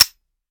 weap_beta_fire_first_plr_01.ogg